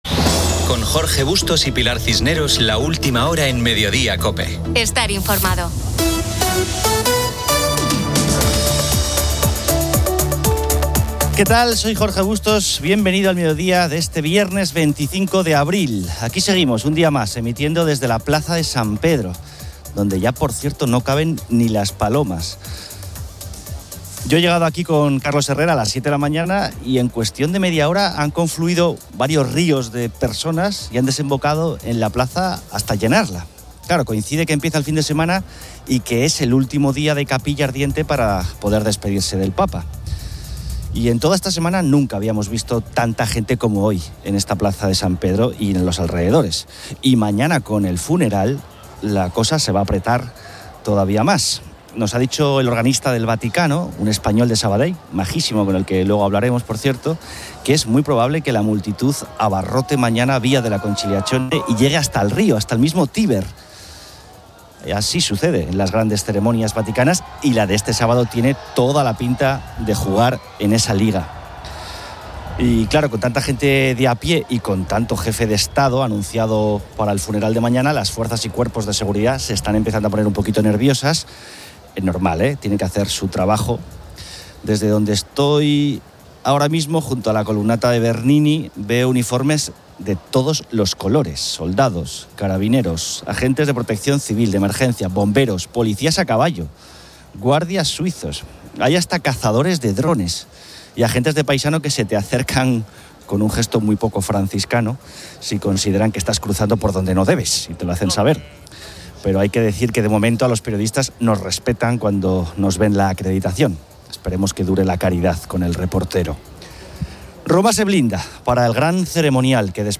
Aquí seguimos un día más emitiendo desde la Plaza de San Pedro, donde ya, por cierto, no caben ni las palomas.